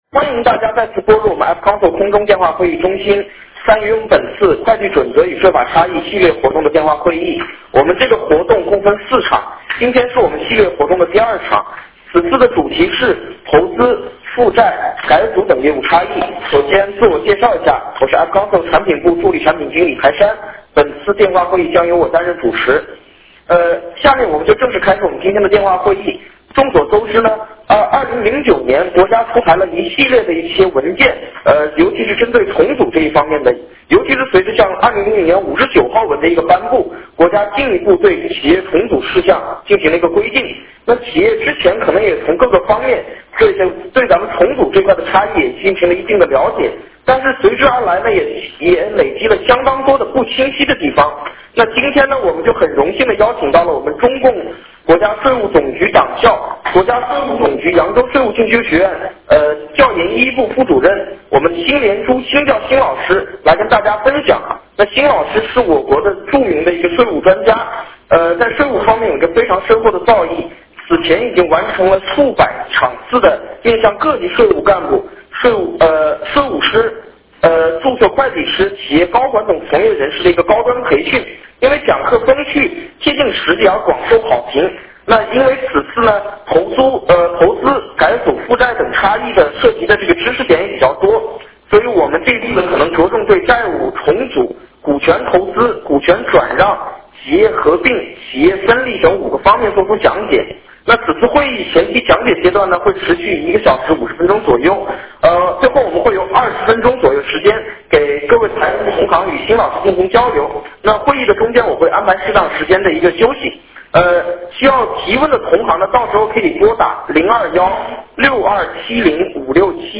电话会议
Q&A 提问与解答环节